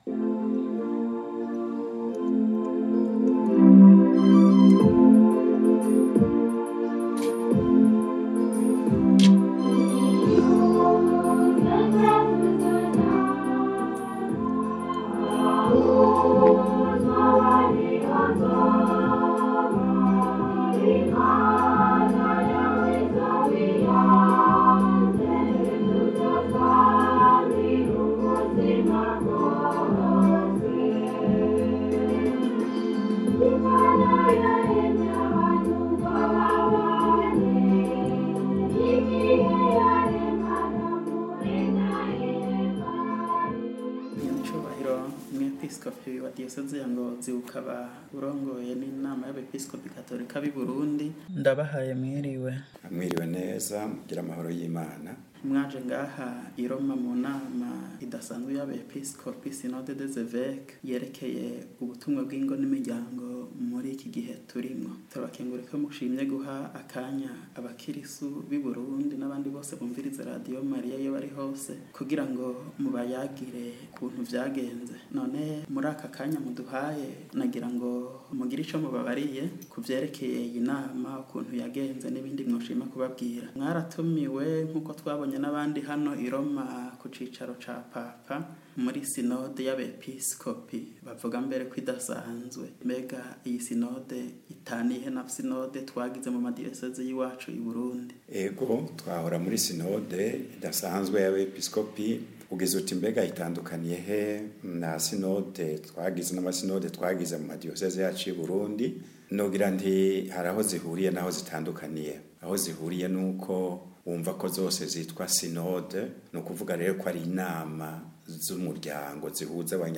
interview-synode.mp3